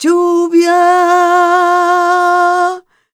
46b05voc-a#m.aif